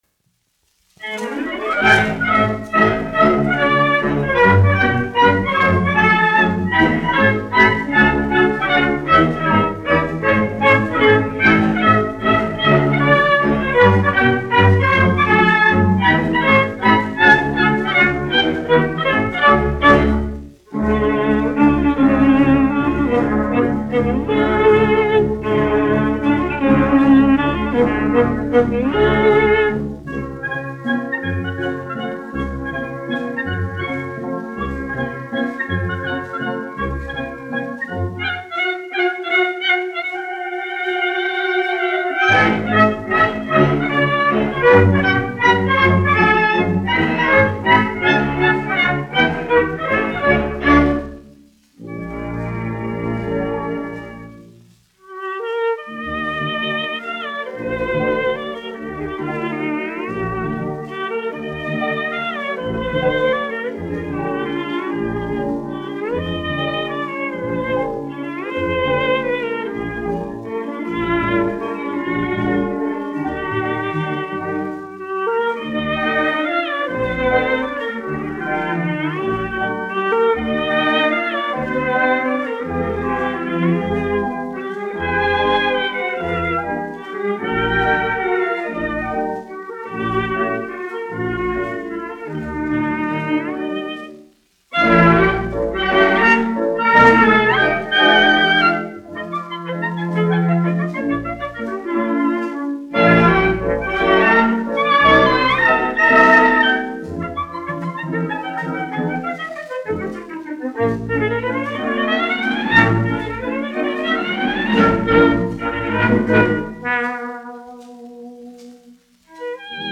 Vadesco (salona orķestris), izpildītājs
1 skpl. : analogs, 78 apgr/min, mono ; 25 cm
Baleti--Fragmenti
Latvijas vēsturiskie šellaka skaņuplašu ieraksti (Kolekcija)